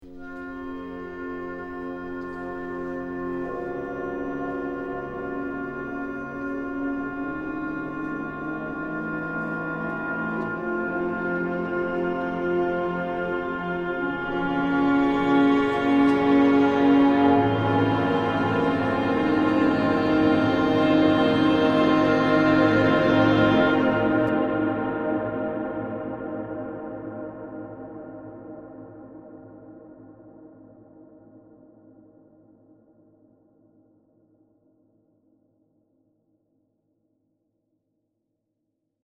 ORCHESTRAL
cautious approach /chamber orchestration  00:39